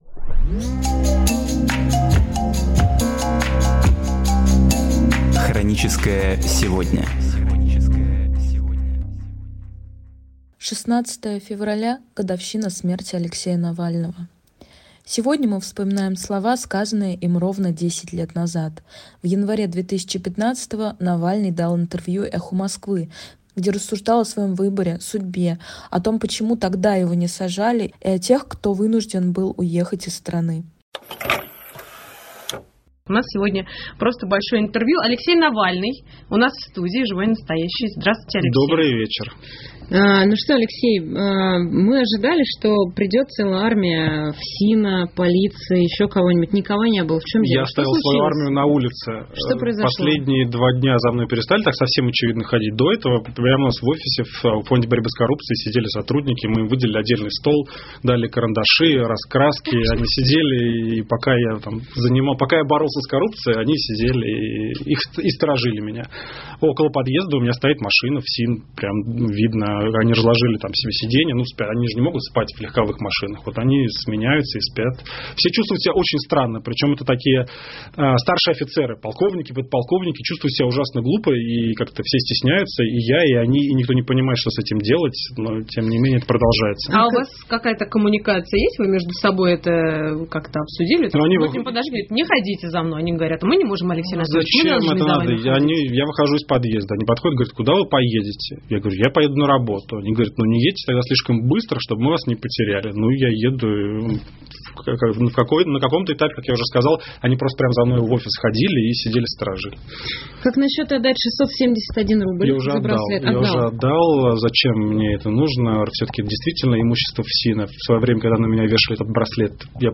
В январе 2015 Навальный дал интервью «Эху Москвы», где рассуждал о своем выборе, судьбе, о том, почему тогда его не сажали, и о тех, кто вынужден был уехать из страны.